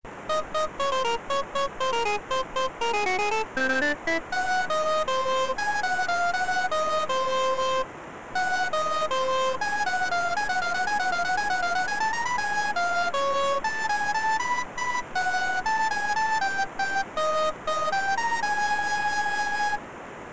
着メロ用オリジナル曲
（HP上の演奏はＸ−６８０３０のFM音源で演奏したものを録音、MP3ファイル化したものです）